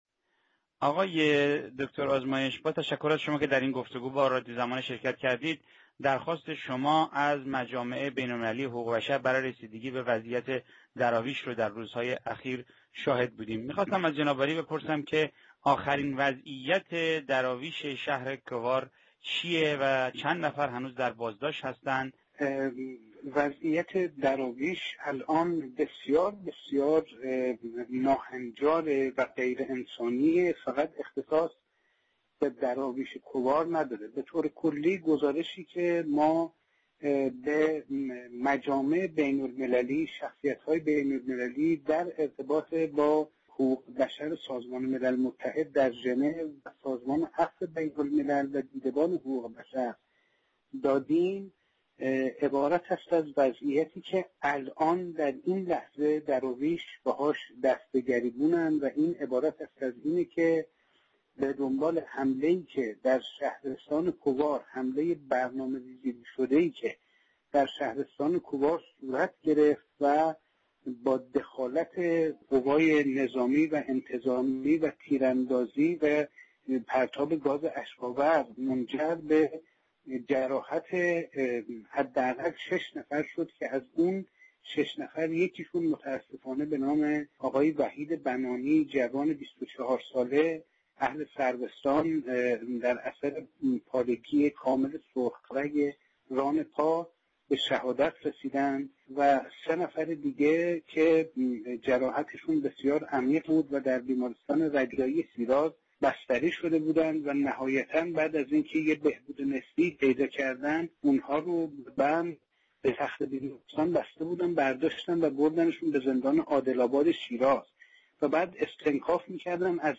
در گفتگو با رادیو زمانه